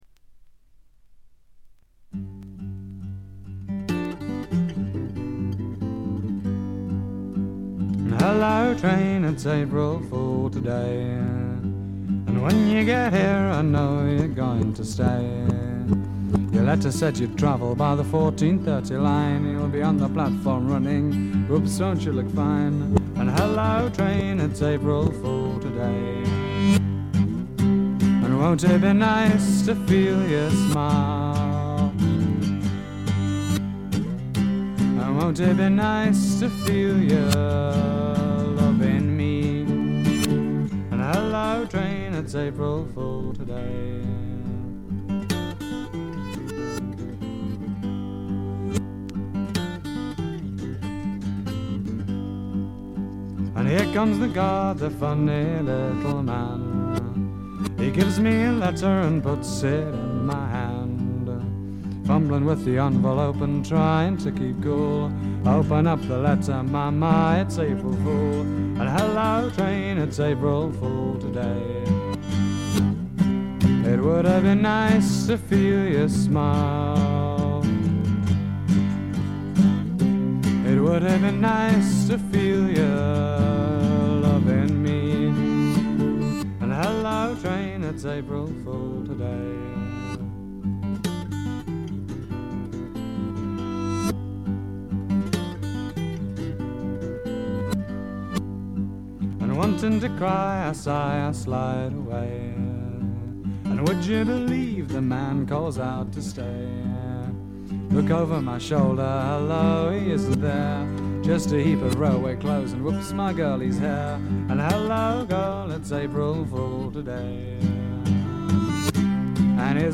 わずかなノイズ感のみ。
試聴曲は現品からの取り込み音源です。